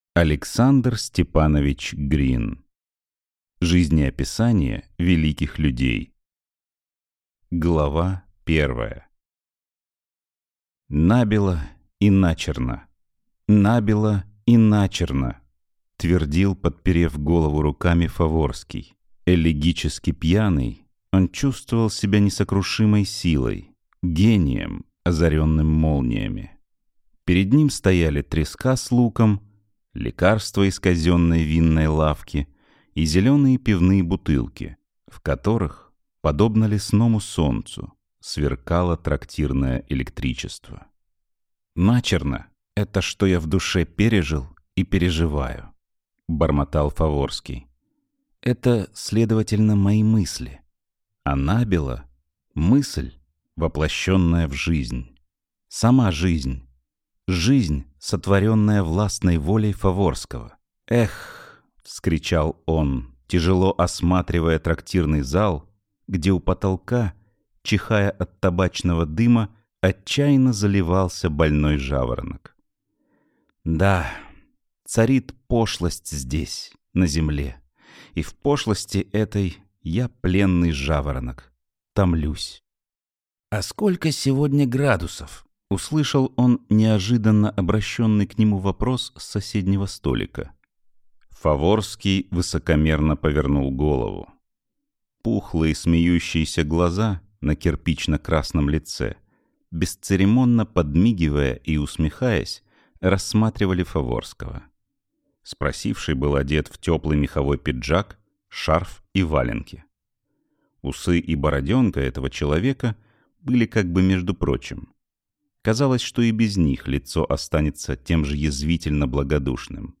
Аудиокнига Жизнеописания великих людей | Библиотека аудиокниг